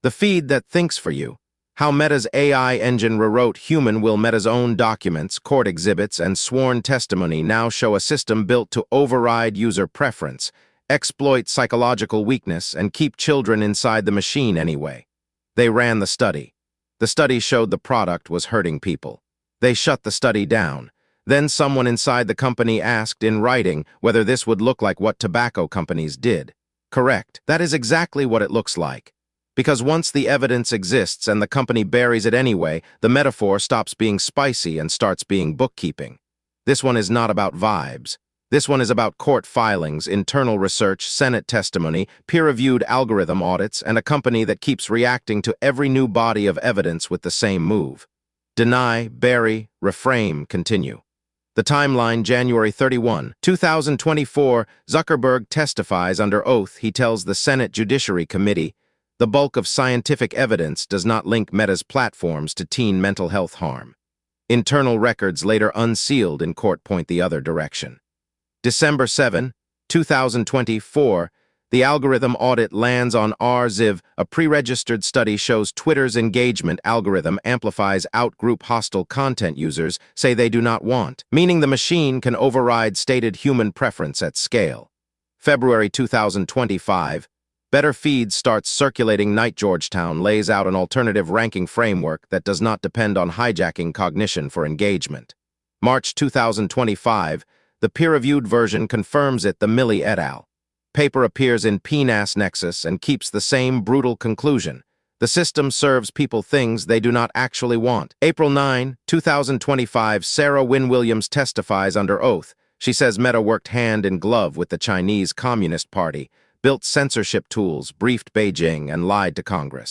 Audio edition ▶ Read this article aloud